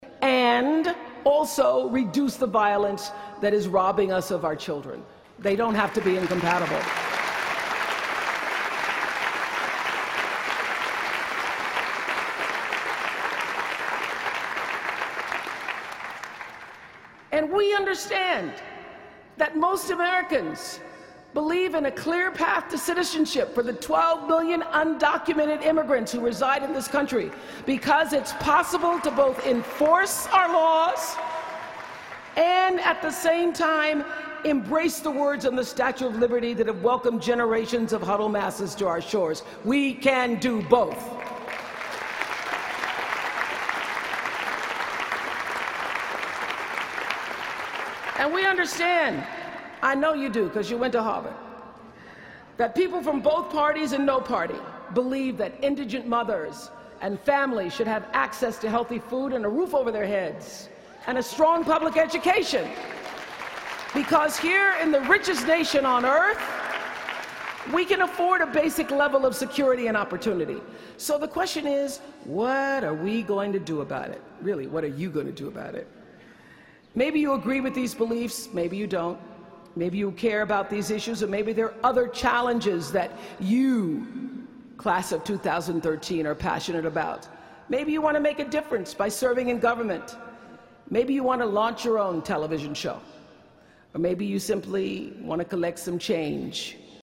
公众人物毕业演讲第362期:奥普拉2013在哈佛大学(11) 听力文件下载—在线英语听力室